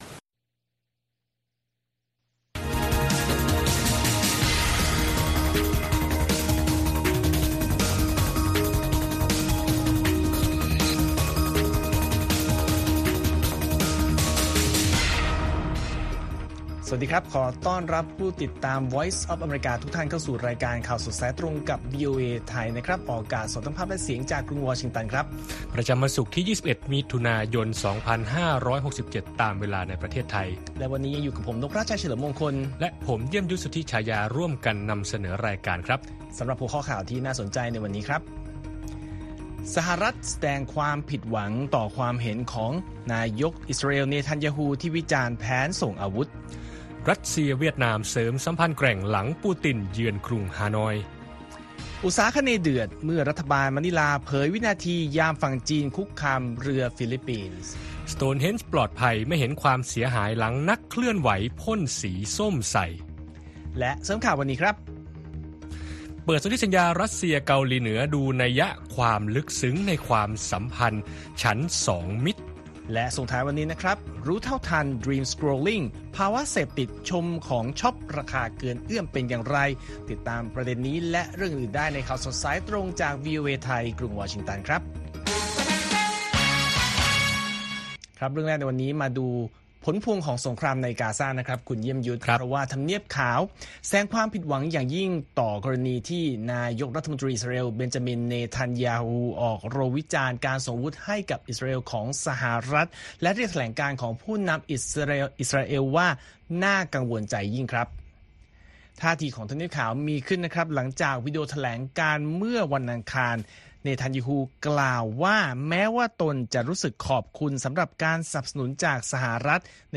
ข่าวสดสายตรงจากวีโอเอไทย 8:30–9:00 น. วันศุกร์ที่ 21 มิถุนายน 2567